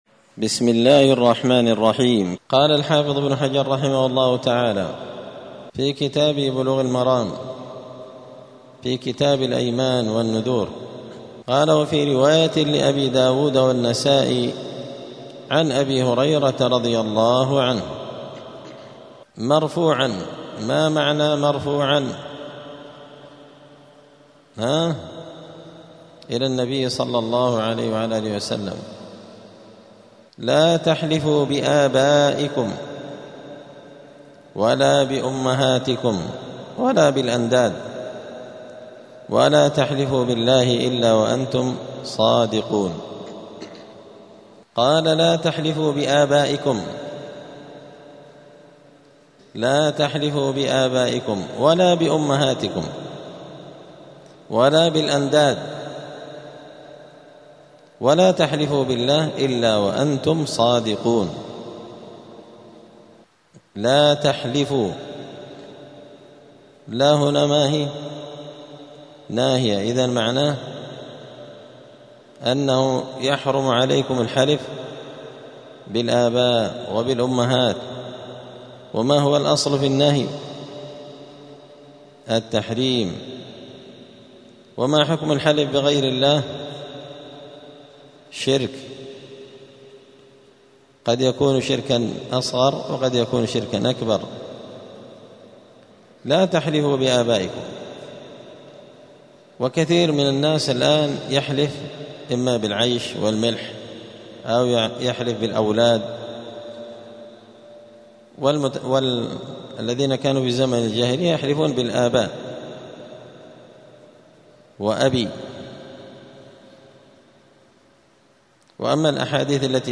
*الدرس الثاني (2) {الحلف بغير الله تعالى}*
دار الحديث السلفية بمسجد الفرقان قشن المهرة اليمن 📌الدروس اليومية